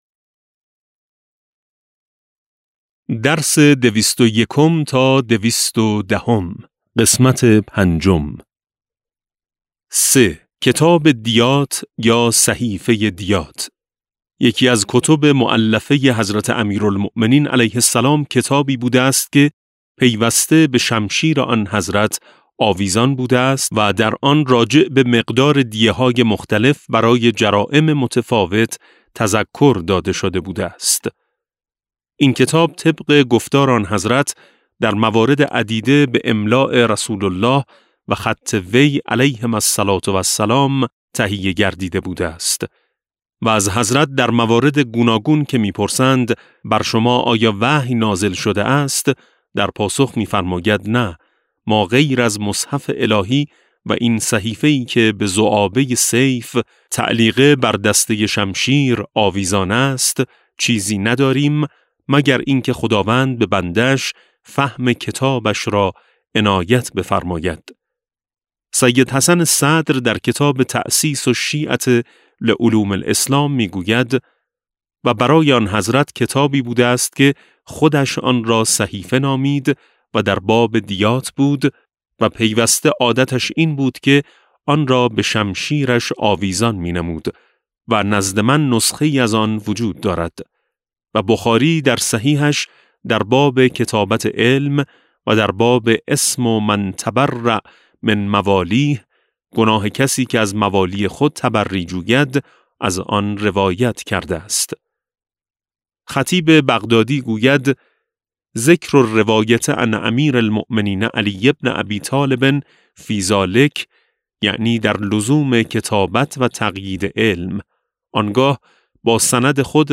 کتاب صوتی امام شناسی ج14 - جلسه13